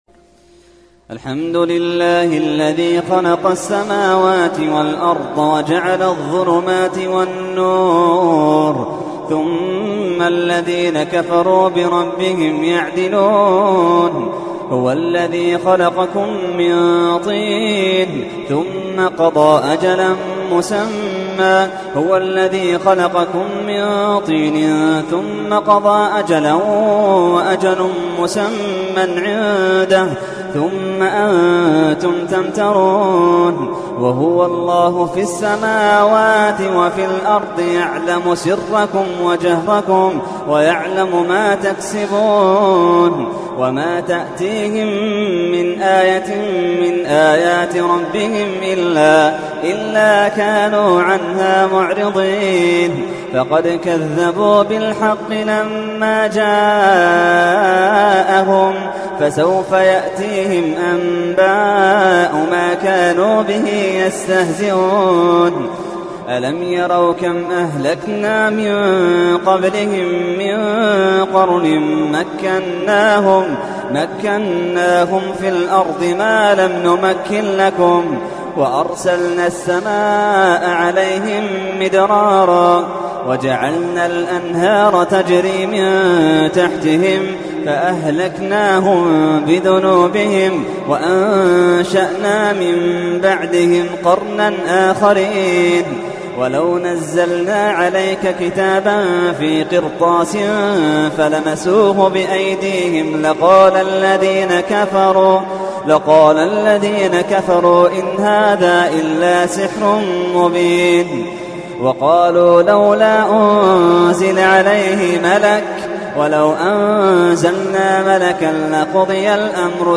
تحميل : 6. سورة الأنعام / القارئ محمد اللحيدان / القرآن الكريم / موقع يا حسين